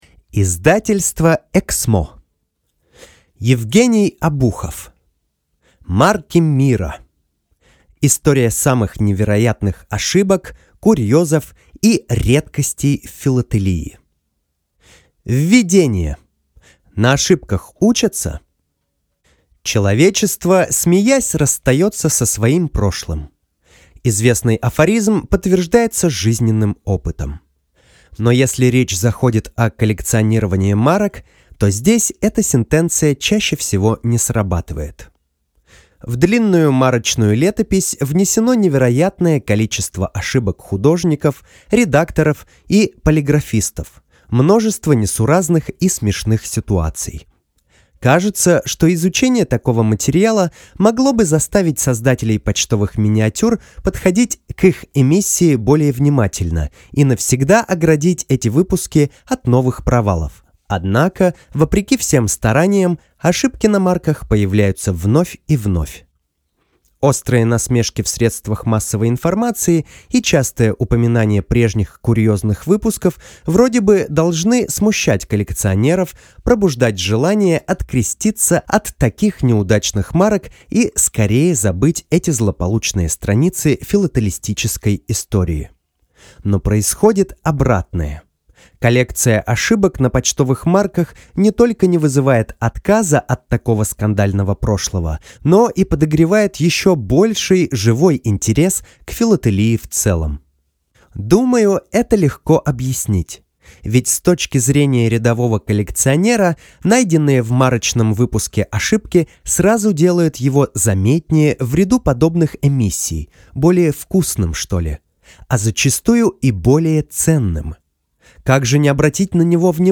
Аудиокнига Марки мира. История самых невероятных ошибок, курьезов и редкостей в филателии | Библиотека аудиокниг